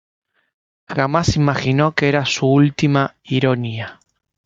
i‧ro‧ní‧a